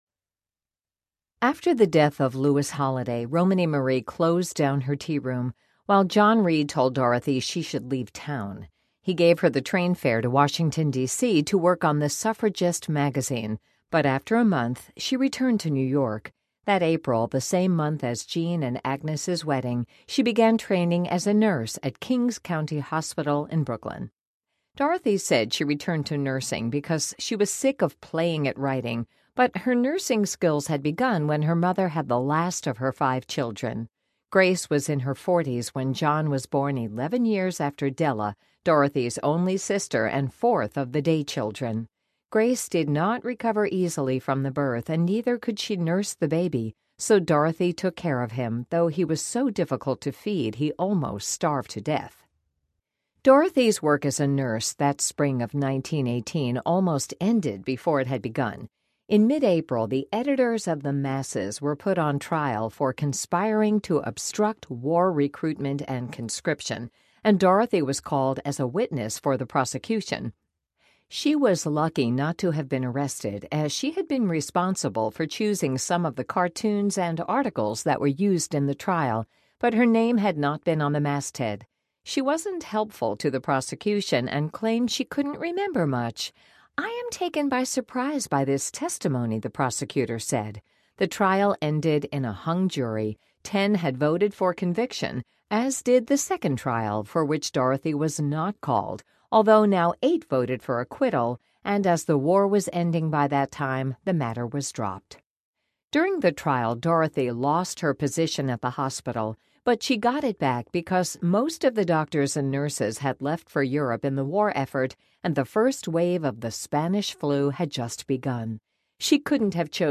Dorothy Day: The World Will Be Saved By Beauty Audiobook
Narrator
13.8 Hrs. – Unabridged